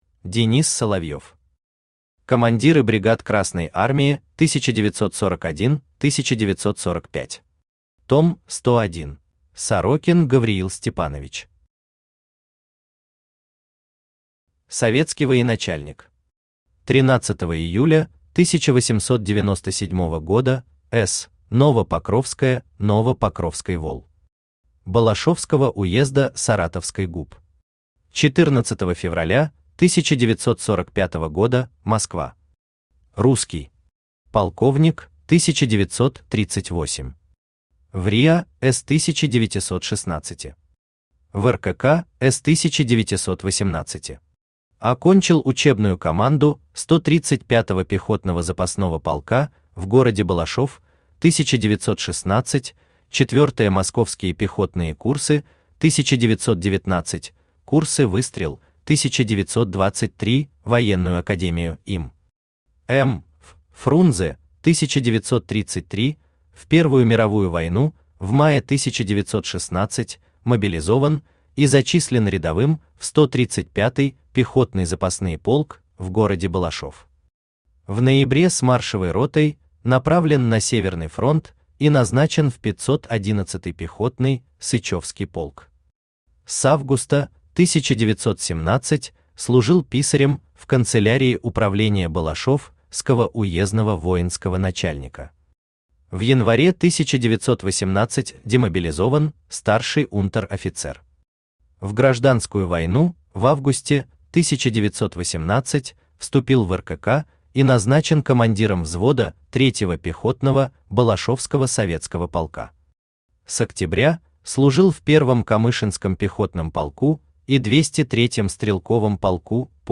Аудиокнига Командиры бригад Красной Армии 1941-1945. Том 101 | Библиотека аудиокниг
Том 101 Автор Денис Соловьев Читает аудиокнигу Авточтец ЛитРес.